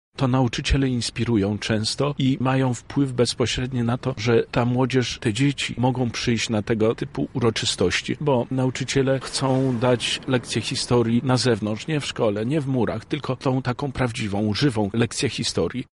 Obchody upamiętniające uczestników tamtych wydarzeń objęły mszę świętą oraz przemarsz pod pomnik Powstańców Styczniowych, gdzie złożone zostały okolicznościowe wieńce i znicze. Uczniowie lubelskich szkół licznie pojawili się na uroczystościach:
-mówi Zbigniew Wojciechowski, wicemarszałek województwa lubelskiego.